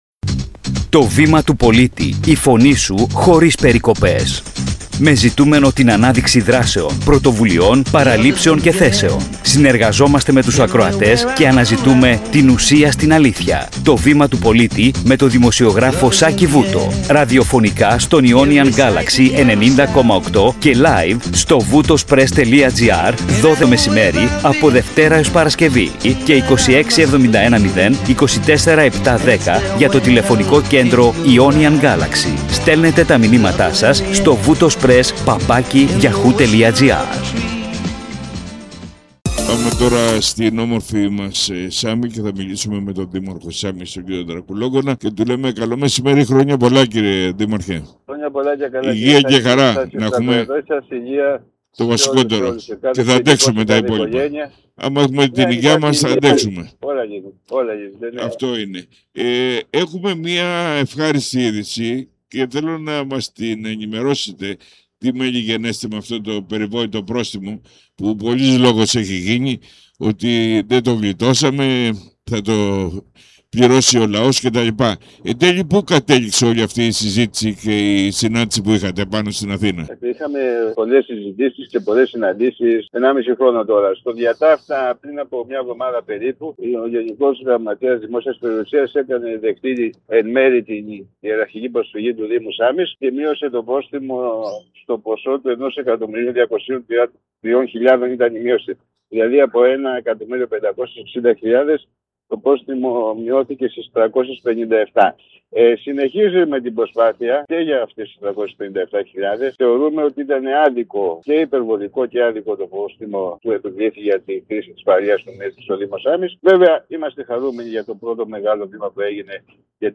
Το Βήμα του Πολίτη – Συνέντευξη Δημάρχου Σάμης